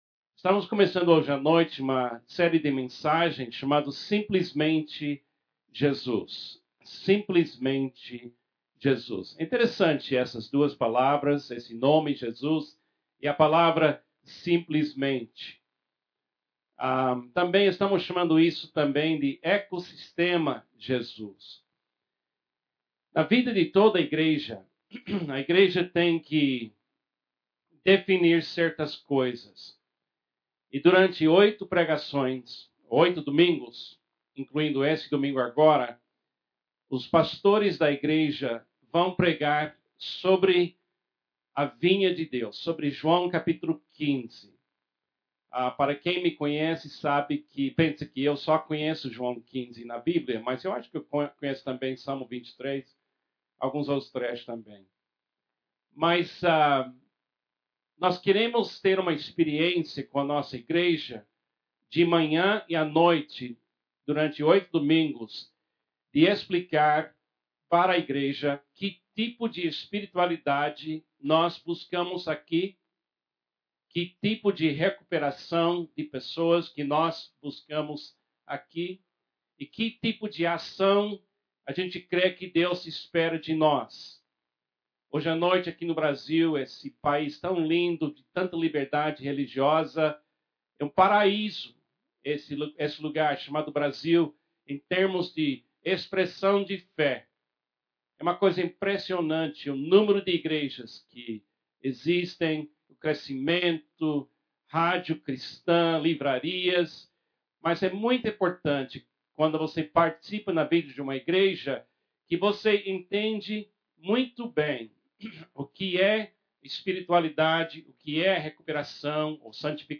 na Igreja Batista Memorial de Alphaville